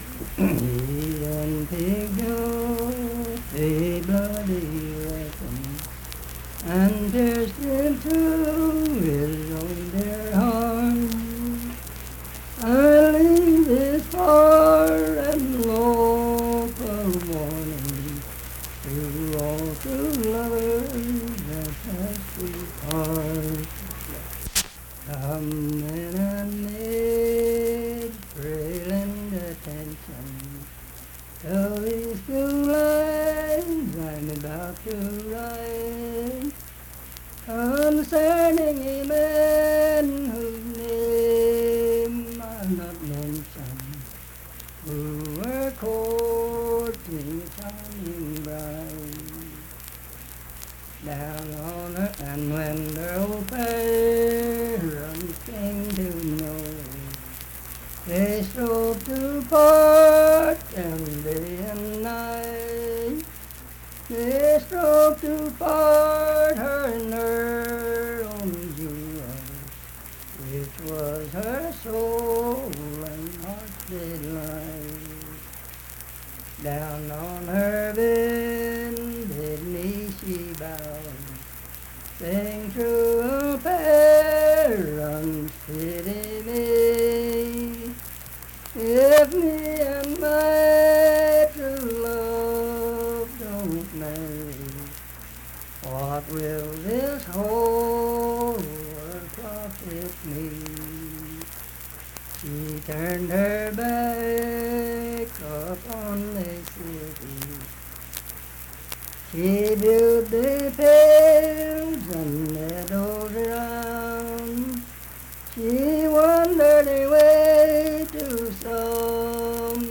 Unaccompanied vocal music
Verse-refrain, 10(4).
Voice (sung)
Harts (W. Va.), Lincoln County (W. Va.)